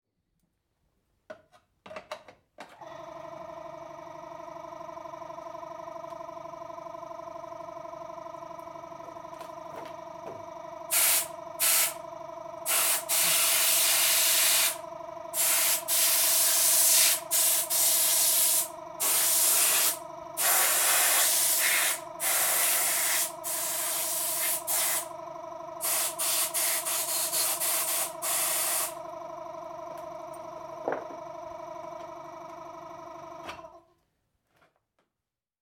Cleaning a Chainsaw with Air Compressor
In this recording you’ll hear an old air compressor in use.
The sound you’ll hear in this clip is the chainsaw being cleaned with compressed air.
• Compressor